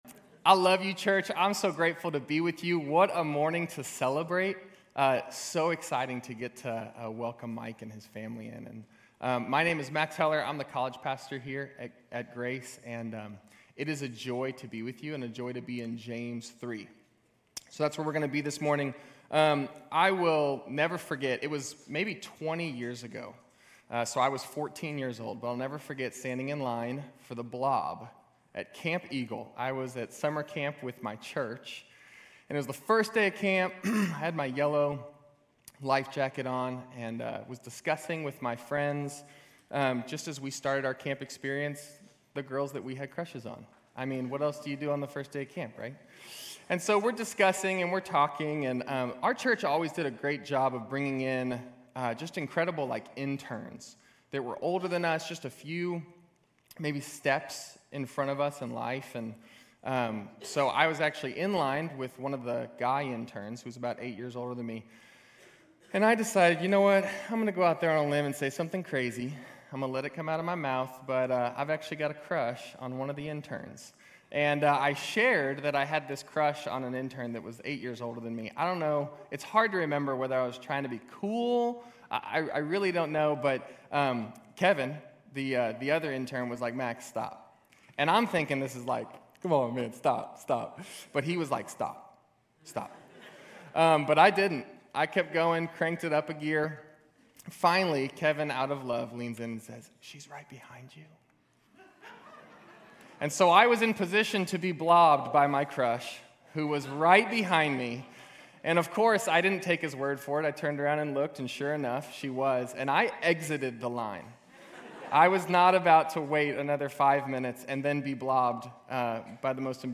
GCC-UB-July-9-Sermon.mp3